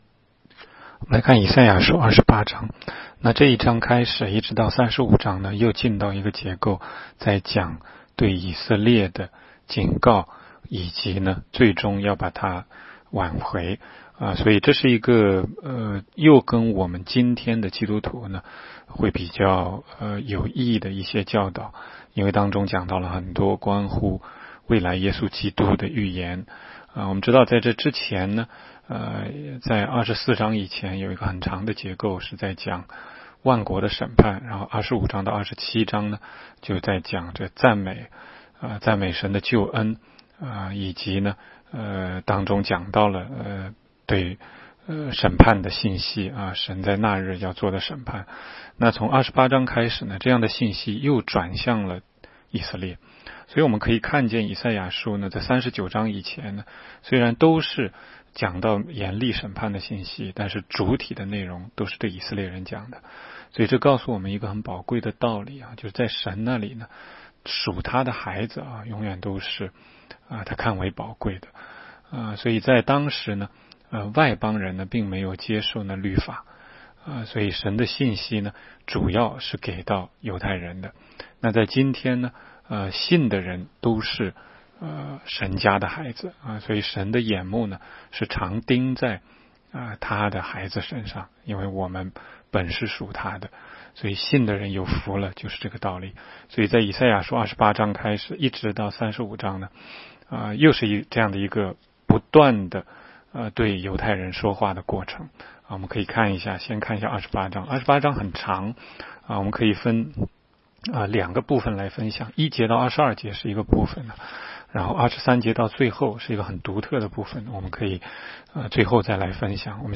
16街讲道录音 - 每日读经 -《 以赛亚书》28章